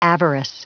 Prononciation du mot avarice en anglais (fichier audio)
Prononciation du mot : avarice